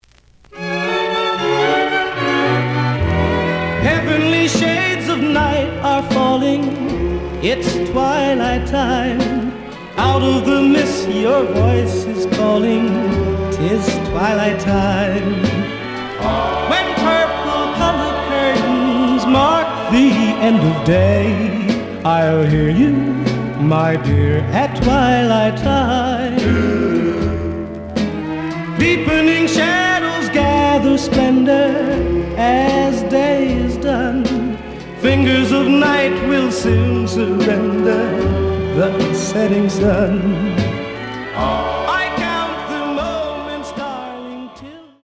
The audio sample is recorded from the actual item.
●Genre: Rhythm And Blues / Rock 'n' Roll
Edge warp. But doesn't affect playing. Plays good.)